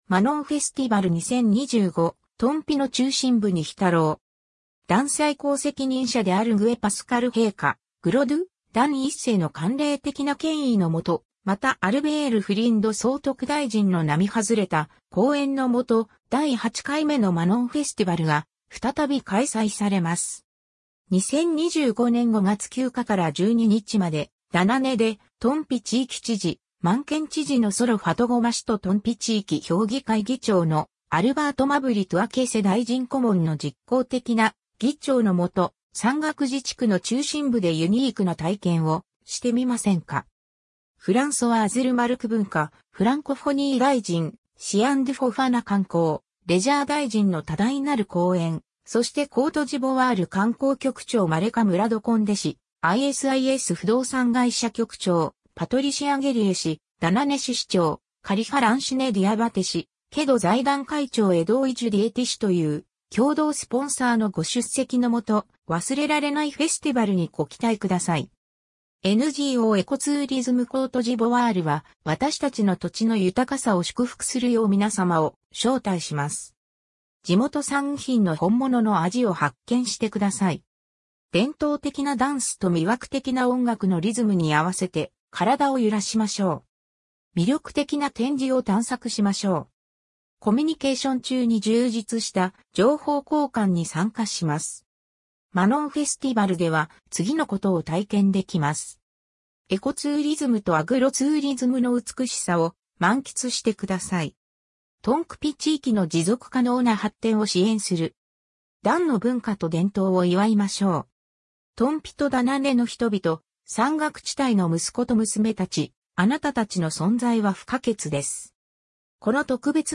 ビデオクリップのオーディオバージョン:
spot-japonais.ogg